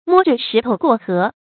摸著石頭過河 注音： ㄇㄛ ㄓㄜ ㄕㄧˊ ㄊㄡ ㄍㄨㄛˋ ㄏㄜˊ 讀音讀法： 意思解釋： 比喻辦事謹慎，邊干邊摸索經驗 出處典故： 柳建偉《英雄時代》第26章：「水淺的時候，可以 摸著石頭過河 。」